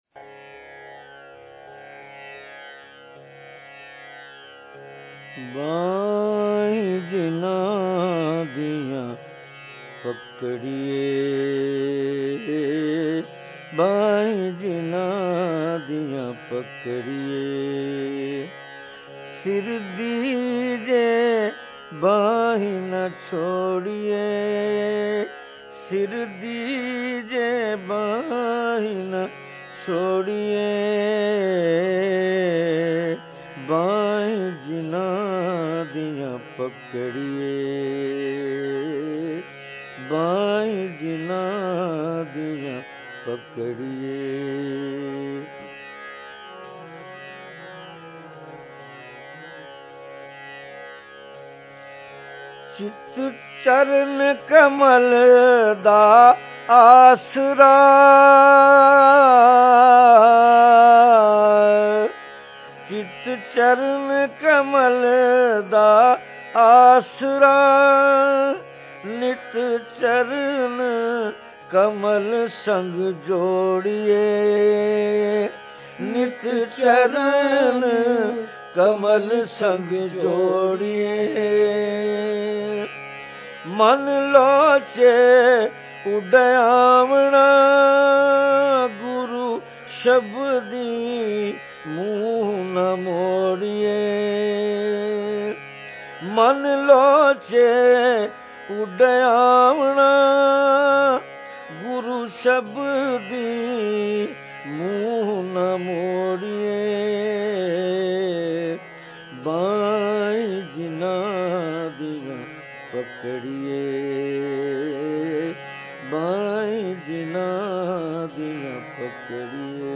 ہم سکھ گرو تيگ بہادر جی کے شبد پڑھنے کے ليے ايک پکی آواز ڈونڈھ رہے تھے جس کی جمي ہوئي لو ميں ايک جوان چيلا اپنی ہوک بھڑکاتا ہے۔
ان کے ساتھ شبد گايا تو سوچا کہ لاہور جا بسوں اور ان سے کیرانہ گھرانہ کی گائيکی سيکھوں جو عطائی ہو کر بھی خاندانی گويوں کو شرما سکتے تھے۔